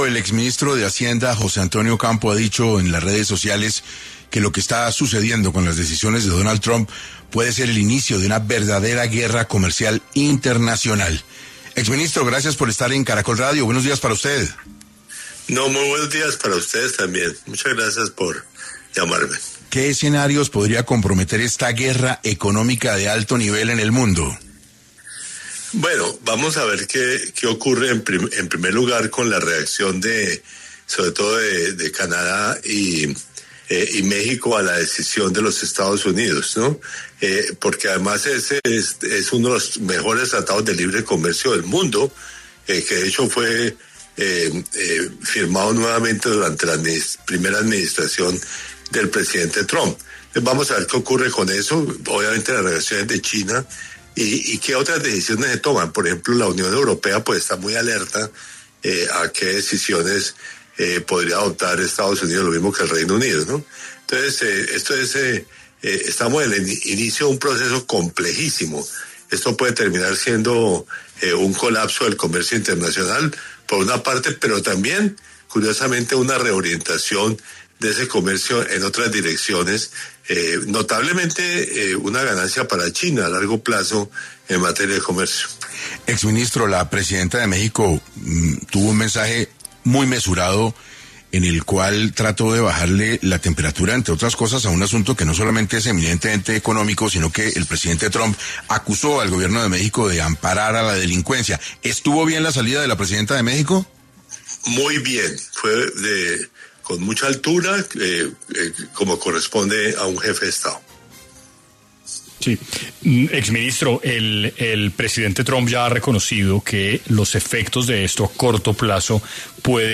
En diálogo con 6AM, el exministro Ocampo dijo que hay que ver lo que ocurre con la reacción, sobre todo, de Canadá y México ante la decisión de los Estados Unidos, porque es uno de los mejores tratados de libre comercio del mundo, que, de hecho, fue firmado nuevamente durante la primera administración del presidente Trump.